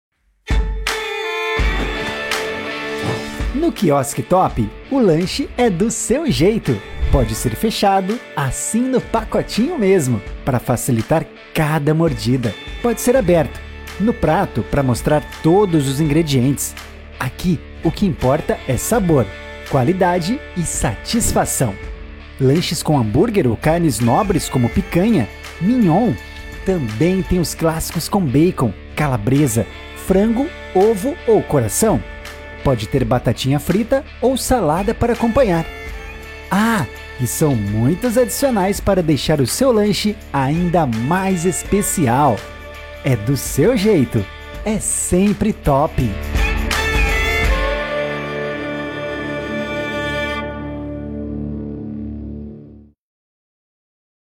Animada